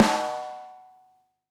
Snare Beta 98:AmpC reverb 8.wav